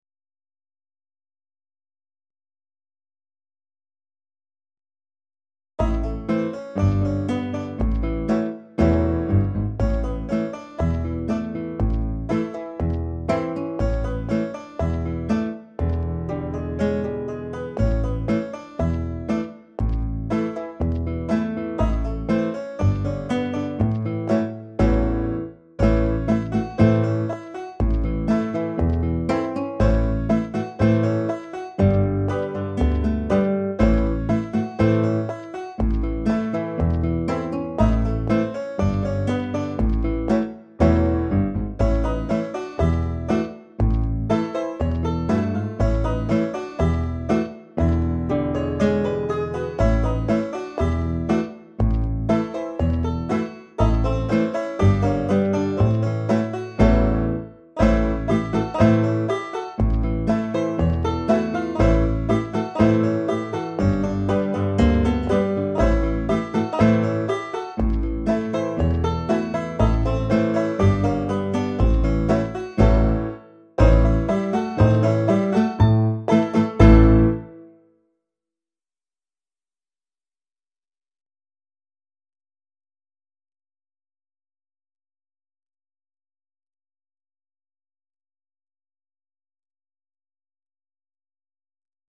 47 Old Joe Clark (Backing Track)